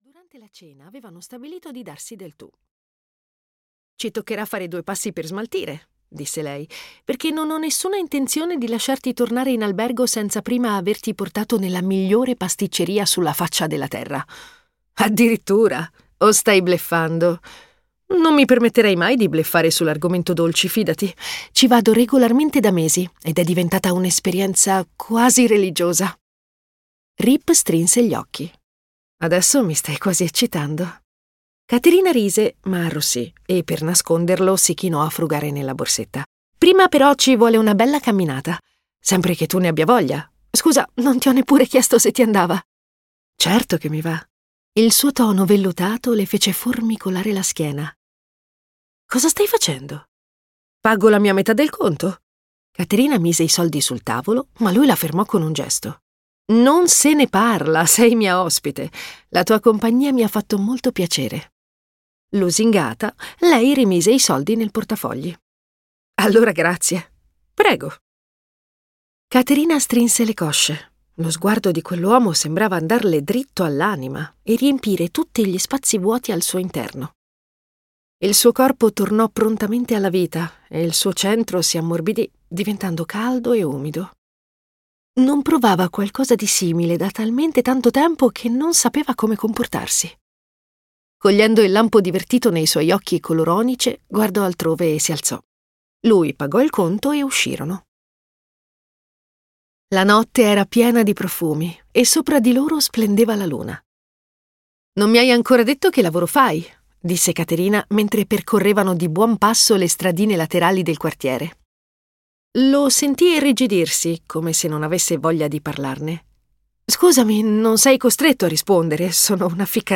"Contratto inevitabile" di Jennifer Probst - Audiolibro digitale - AUDIOLIBRI LIQUIDI - Il Libraio